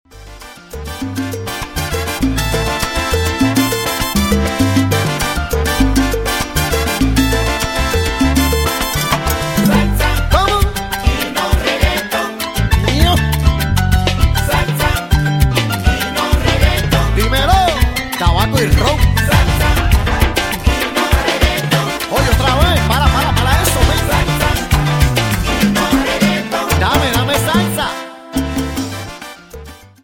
Salsa Charts - November 2008